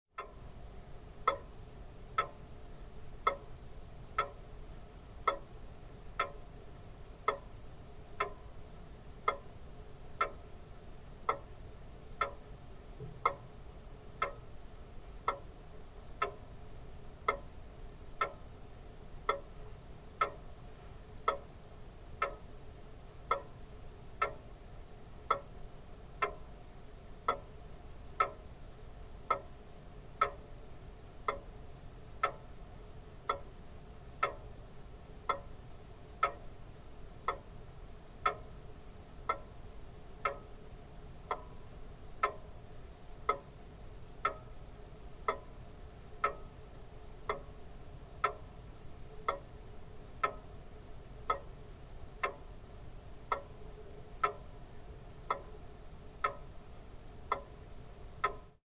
Month-going Longcase clock by George Stratford, London, 1705
This is a recording made with a contact microphone of the internal mechanism of a month-going Longcase clock by George Stratford. The clock was made in 1705 and is in the collection of the Clockmaker's Museum, Guildhall, London.